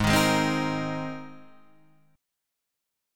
G#add9 chord {4 6 6 5 4 6} chord